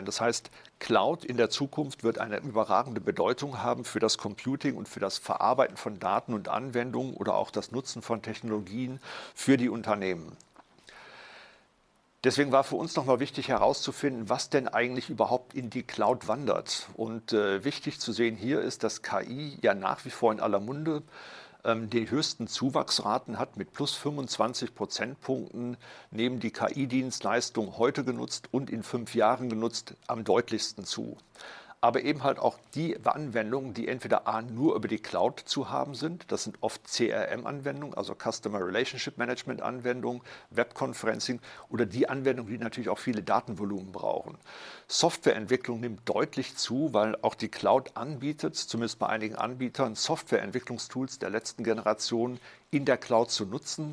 Mitschnitte der Pressekonferenz
bitkom-pressekonferenz-cloud-report-2025-audio-mitschnitt-ki-aus-der-cloud.mp3